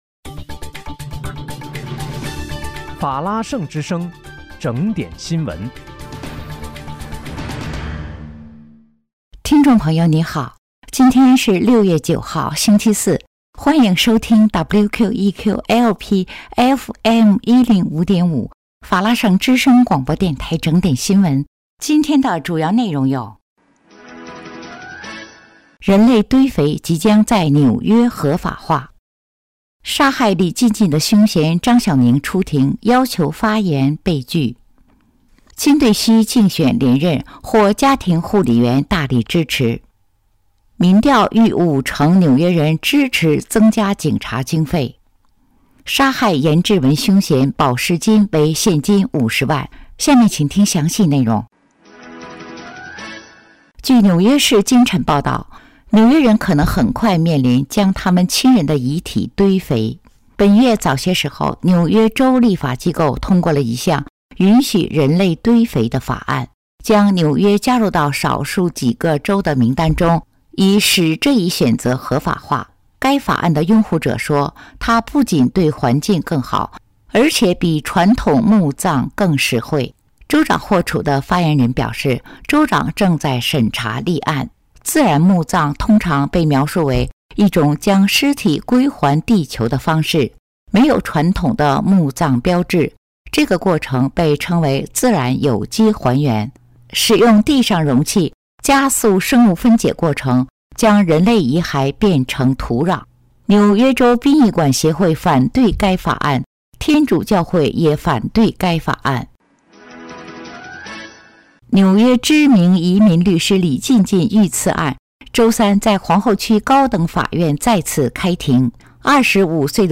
6月9日（星期四）纽约整点新闻